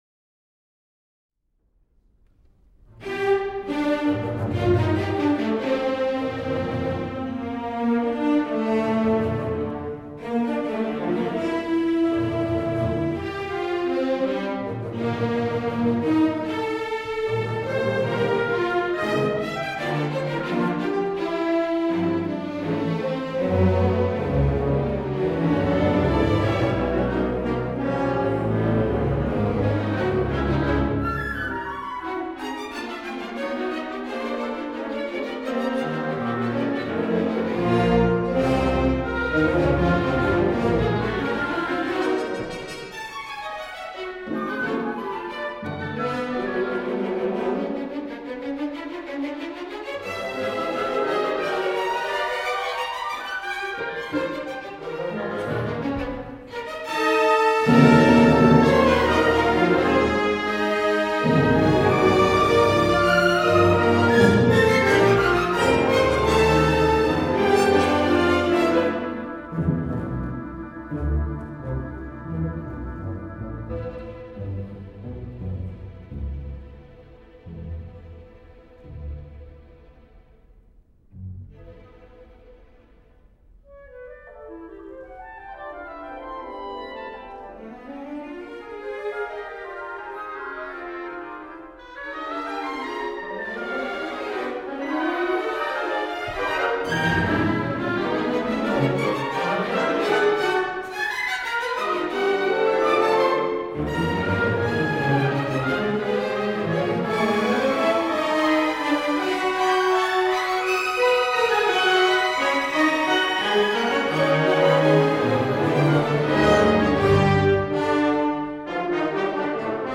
World premiere recording